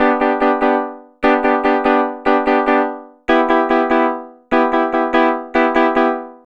Twisting 2Nite 6 Piano-G.wav